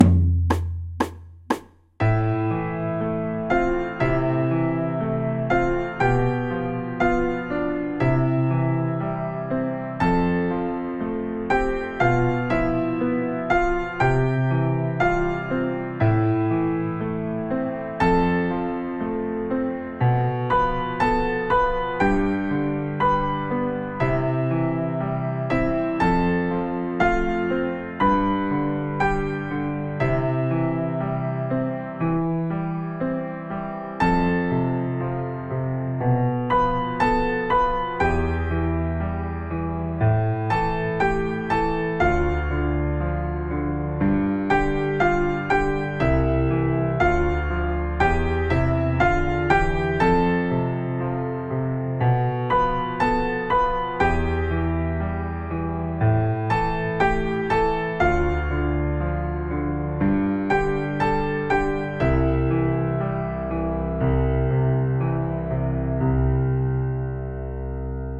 Morceaux à quatre mains motivants
Versions allegro